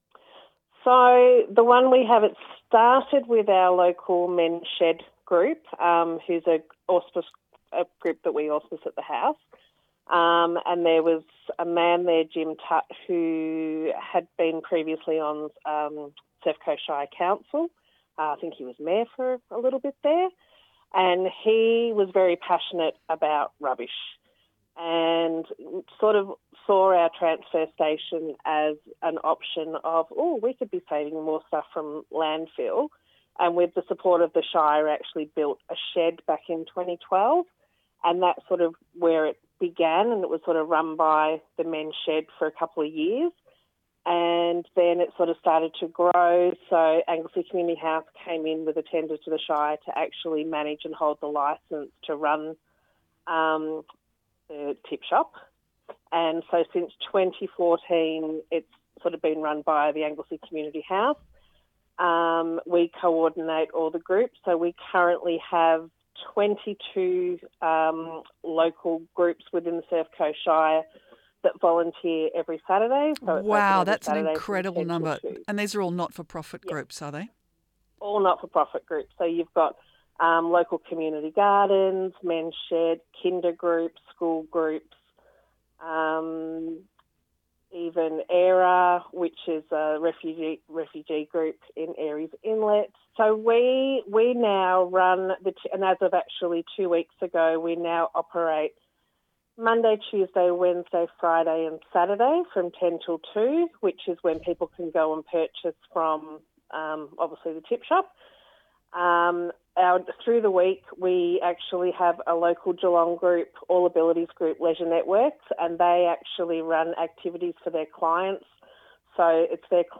You can hear part of the interview below.